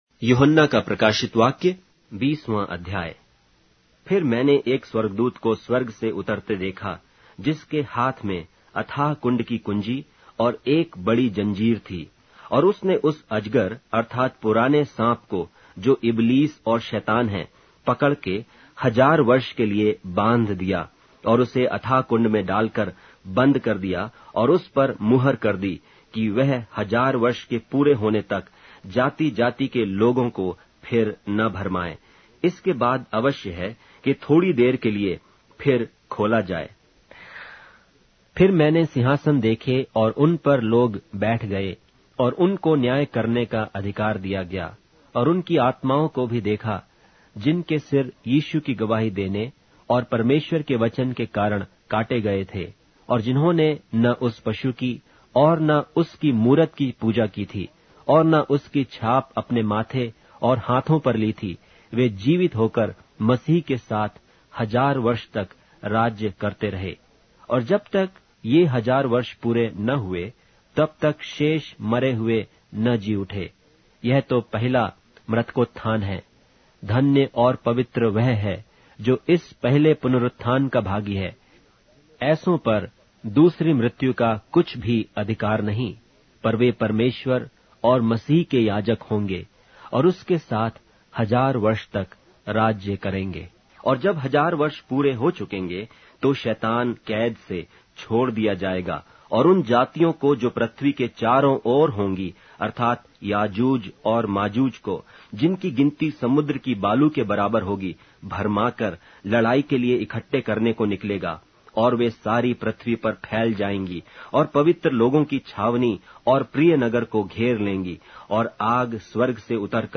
Hindi Audio Bible - Revelation 13 in Ocvta bible version